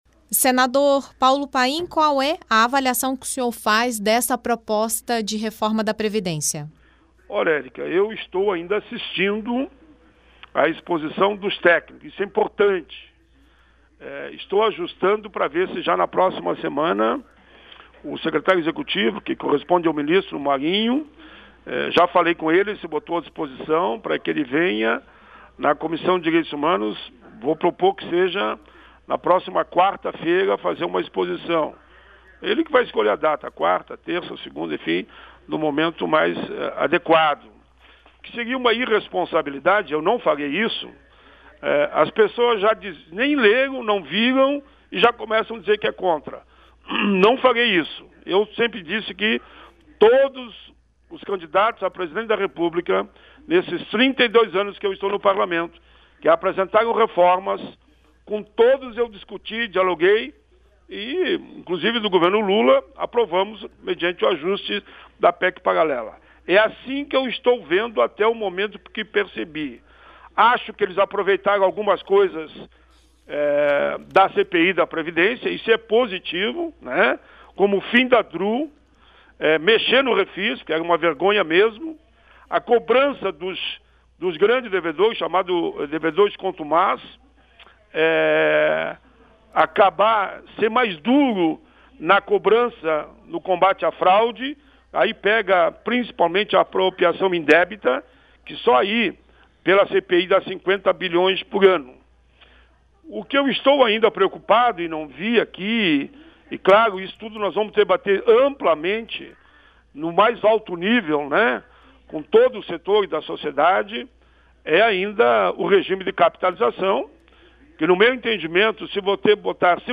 O senador Paulo Paim (PT-RS) falou à Rádio Senado sobre a proposta de reforma da Previdência entregue ao Congresso Nacional nesta quarta-feira (20) pelo presidente da República, Jair Bolsonaro. Na avaliação do senador existem pontos positivos na proposta, em especial medidas de cobrança aos grandes devedores e outros pontos que precisam ser debatidos, como a questão da capitalização.